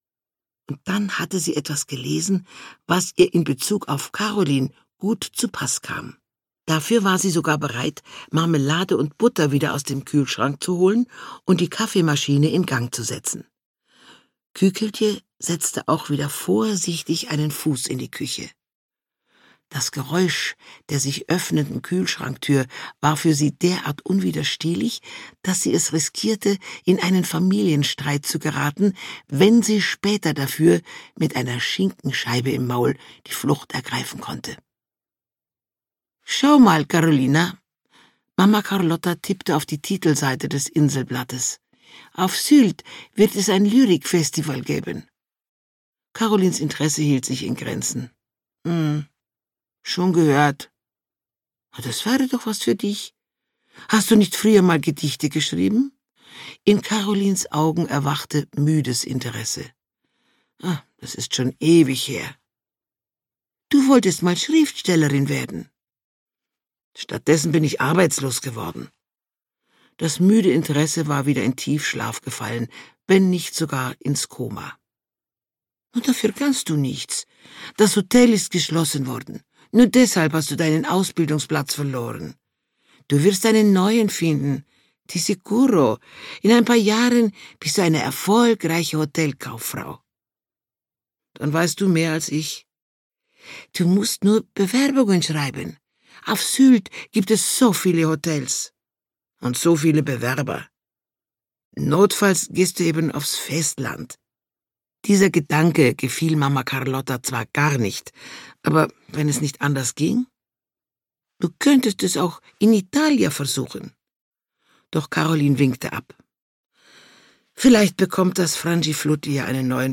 Sturmflut (Mamma Carlotta 13) - Gisa Pauly - Hörbuch